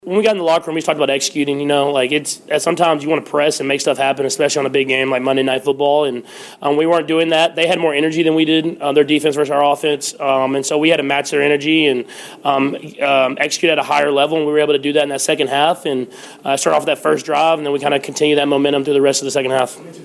The Chiefs scored 21 points in the second half, and after the game, QB Patrick Mahomes talked about it.